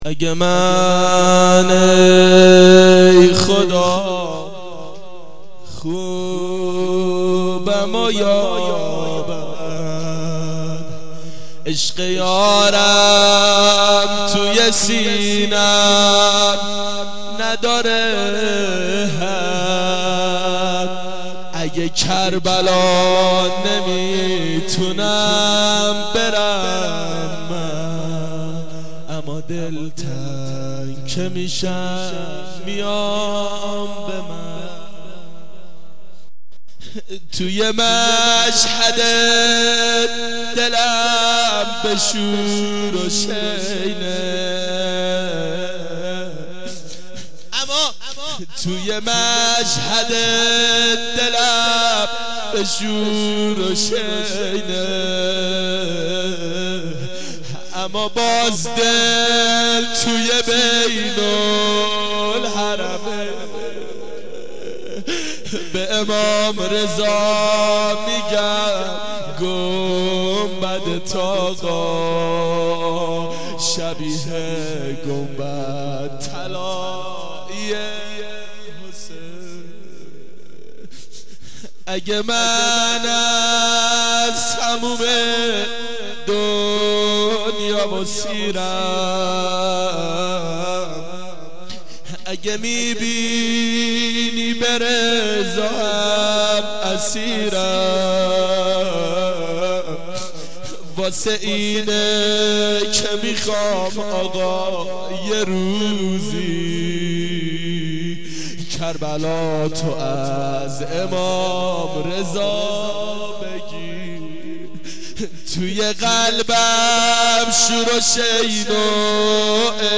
گلچین جلسات هفتگی سال 1387